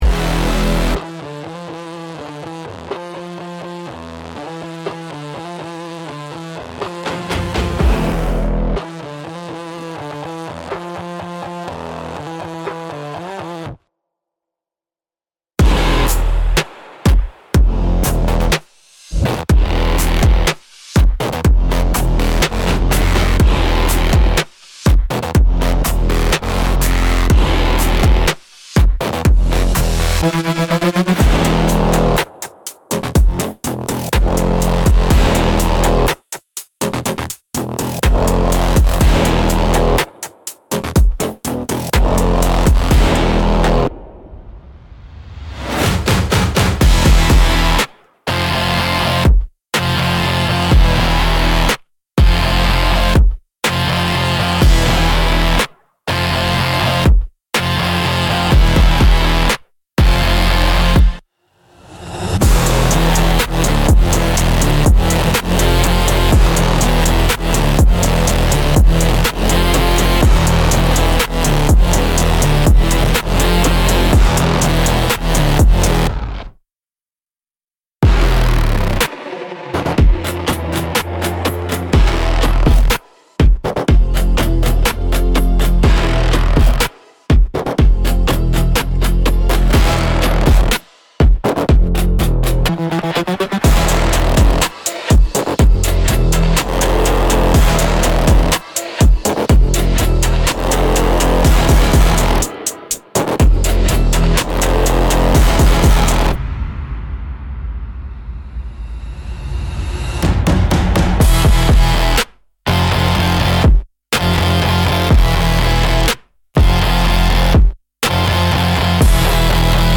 Instrumental - Cinematic Burst x Future Hip-Hop